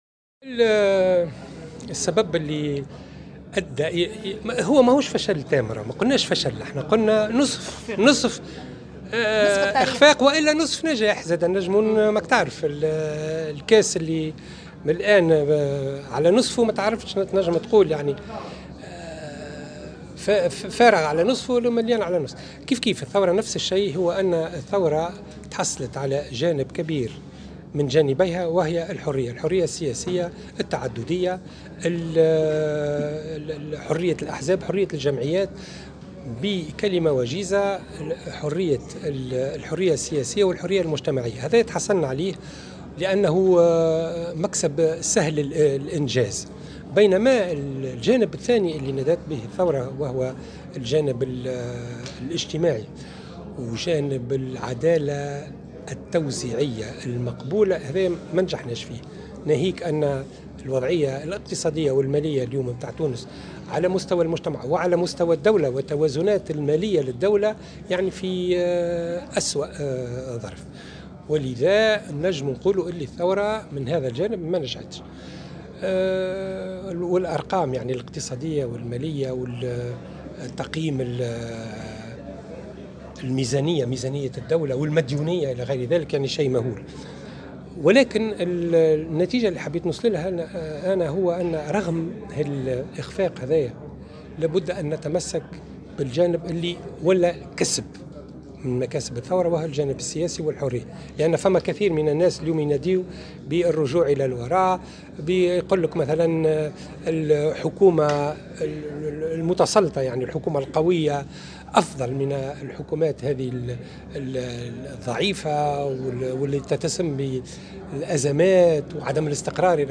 بيّن أستاذ القانون الدستوري عياض بن عاشور في تصريح لموفد "الجوهرة اف أم"، على هامش مشاركته في ندوة نظمها الإتحاد العام التونسي للشغل بعنوان تسع سنوات بعد الثورة، أن الثورة نجحت بالحرية التي اكتسبتها الأحزاب والجمعيات ولم تنجح في جانبها المجتمعي للأفراد والدولة بتفاقم العجز والمديونية والتوازنات المالية للدولة .